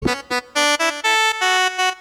Sms сообщение
Несколько нот